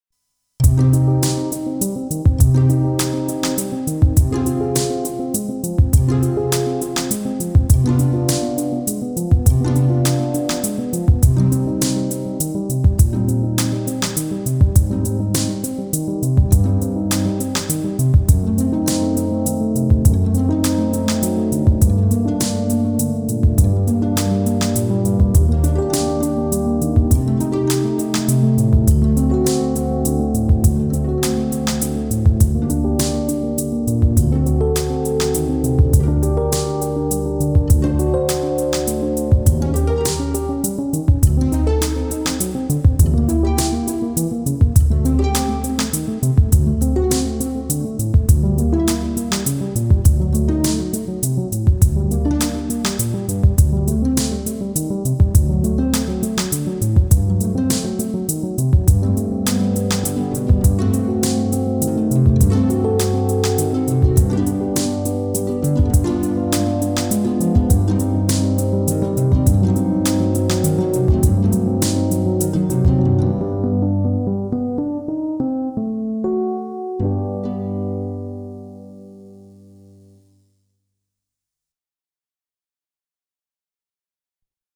ポップスサウンドに
このポップスアレンジと